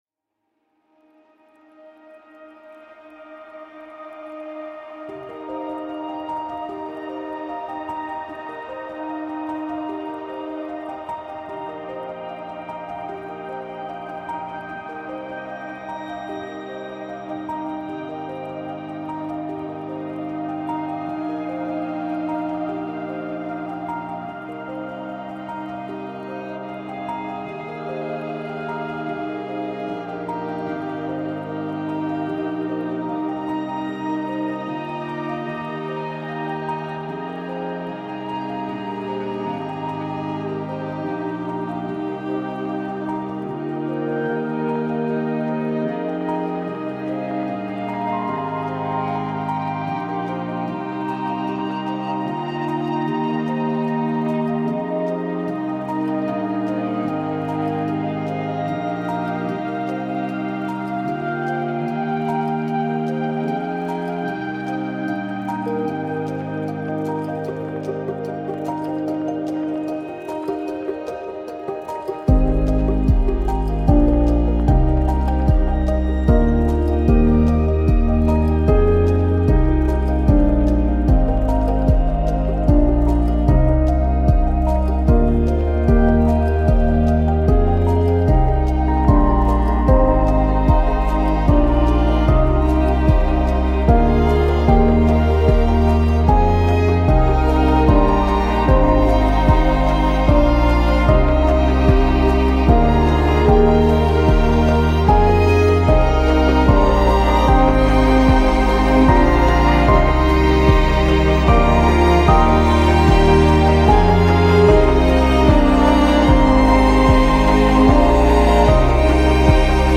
امبینت